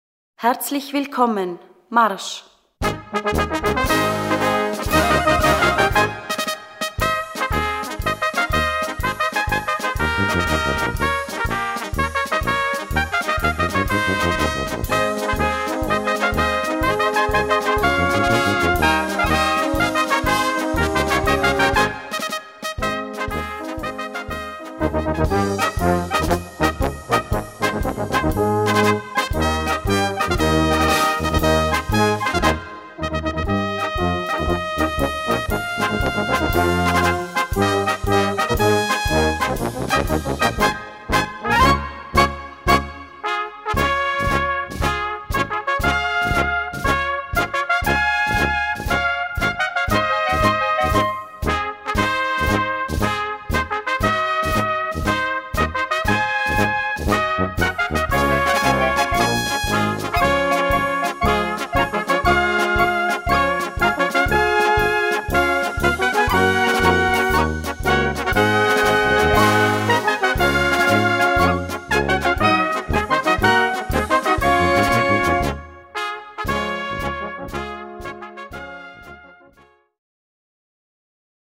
Gattung: Marsch
B-C Besetzung: Blasorchester Zu hören auf